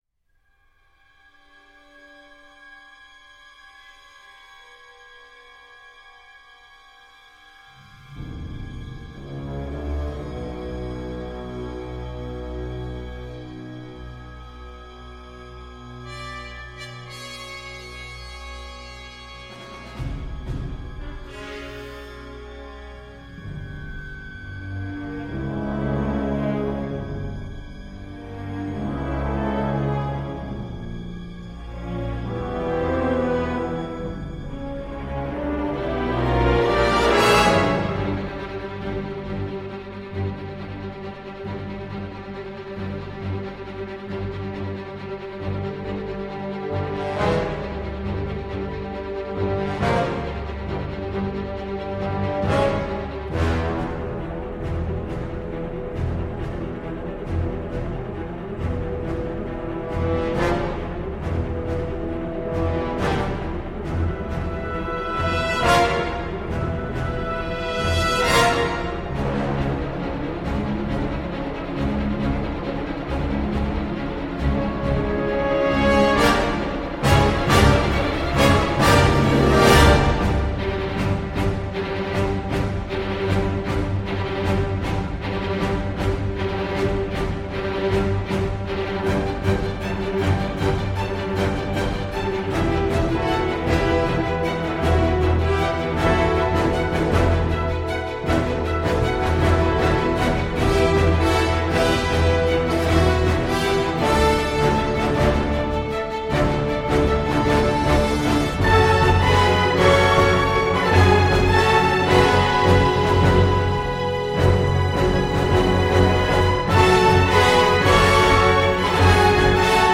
Voilà un score qui se construit lentement.